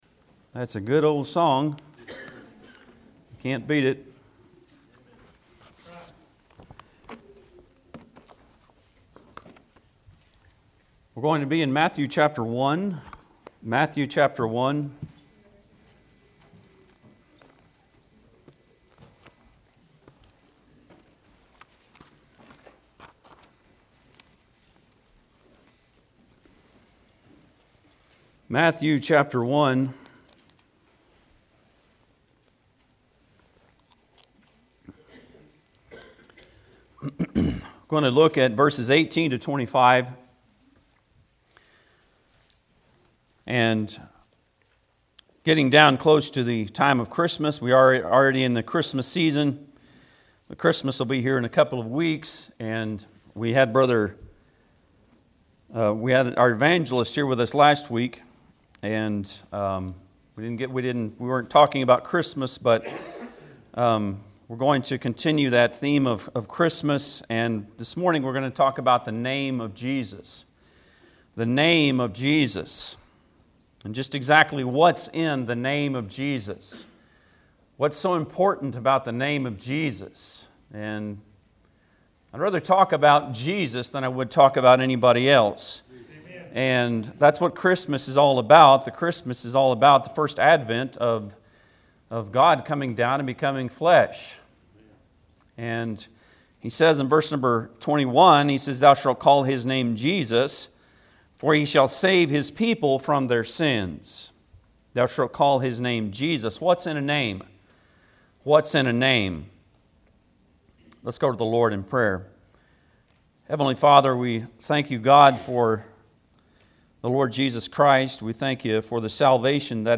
Matthew 1:18-25 Service Type: Sunday am Bible Text